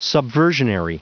Prononciation du mot subversionary en anglais (fichier audio)
Prononciation du mot : subversionary